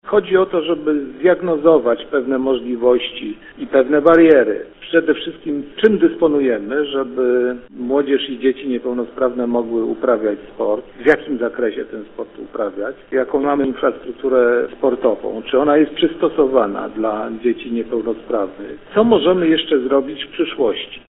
Starosta giżycki Mirosław Drzażdżewski tłumaczy, że polegać będą one na diagnozie problemów, jakie napotykają niepełnosprawni chociażby w korzystaniu z obiektów sportowych.